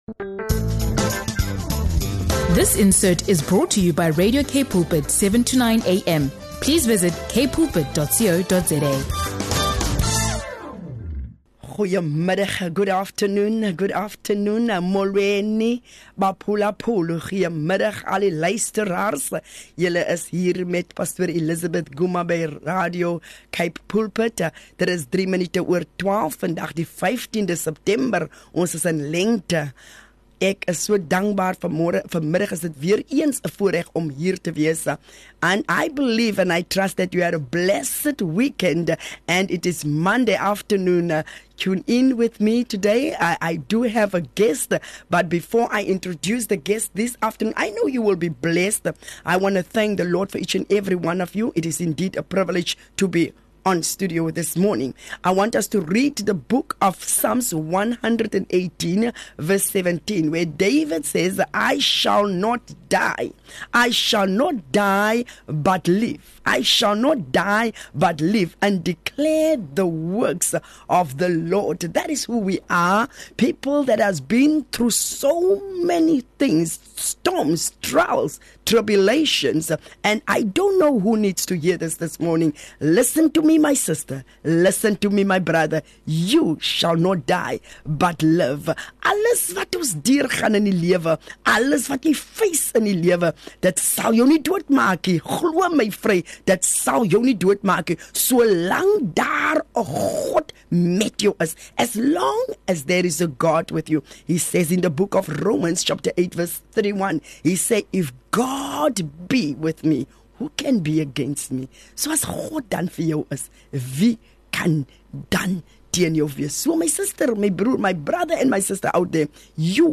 Joining her is a remarkable guest, a woman of God who courageously shares her testimony of surviving childhood rape, finding healing through Jesus Christ, and stepping fully into her divine calling.